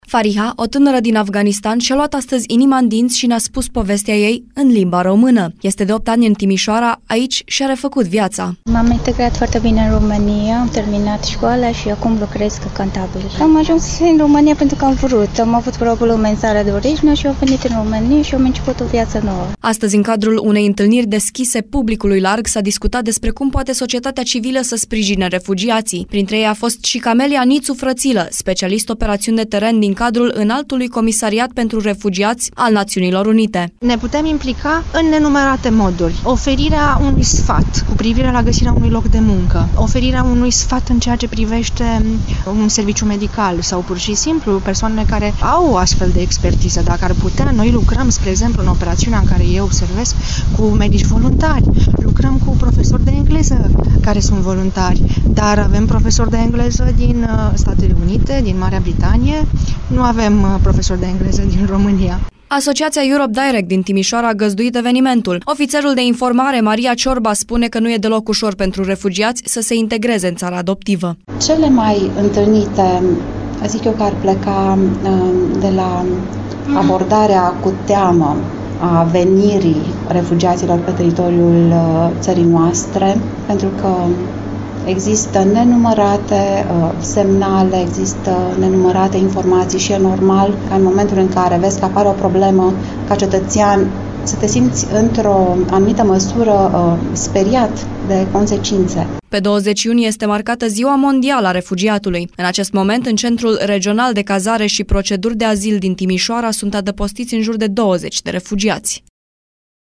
Evenimentul a avut loc la sediul Centrului Europe Direct din Timişoara.